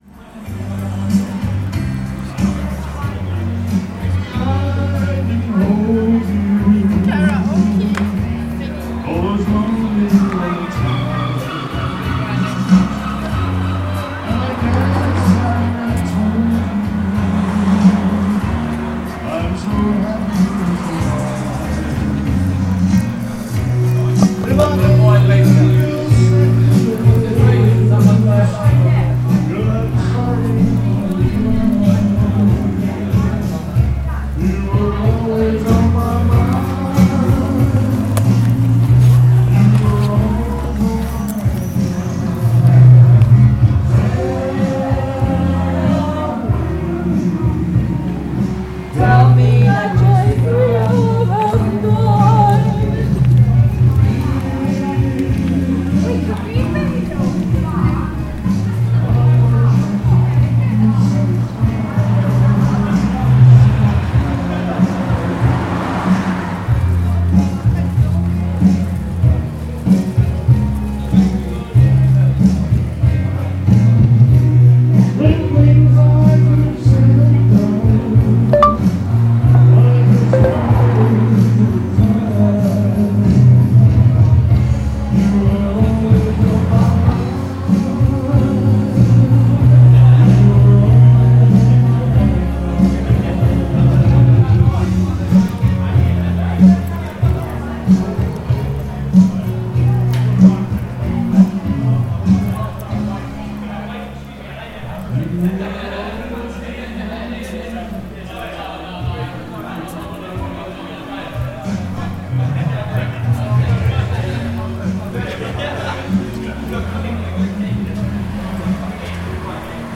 Elvis impersonator, Margate
The Wig & Pen hosts an Elvis impersonator.
Part of the Cities and Memory Margate sound map for Dreamland.